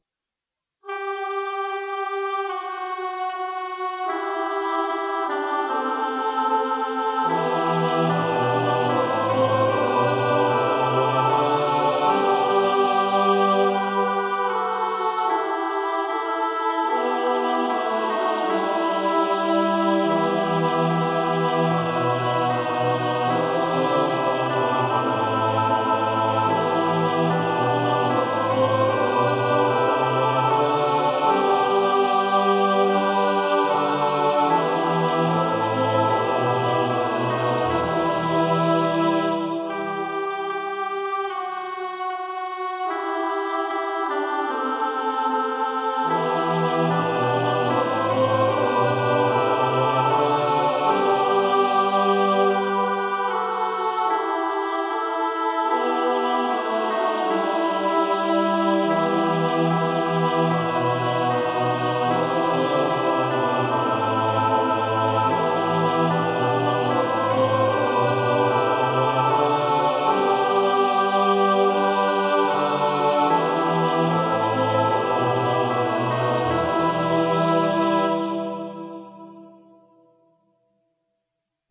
SATB
pro smíšený sbor